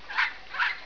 Wissenswertes ueber Wellensittiche :: Wellensittich Audio files
Hier jetzt einige "Songs" von unseren Sittichen, beim Schlummerstündchen und während des Spielens aufgenommen.
Chicos "Huhu"